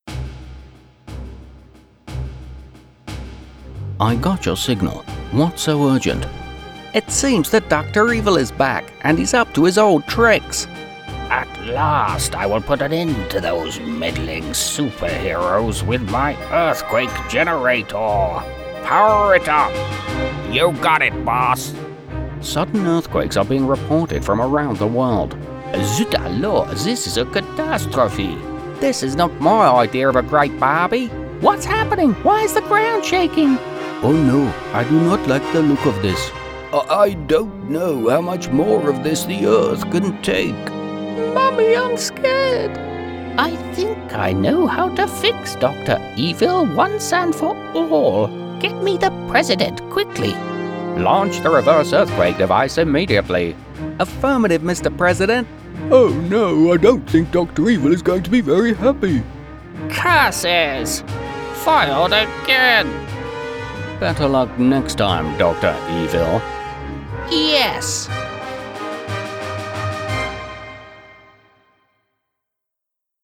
Male
Adult (30-50), Older Sound (50+)
British UK English male - Amusing, Attractive, Authoritative, Believable, Calming, Classy, Comedy, Commercial, Conversational, Corporate, Educational, Friendly, Informative, Natural
Video Games
Words that describe my voice are Attractive, Believable, Conversational.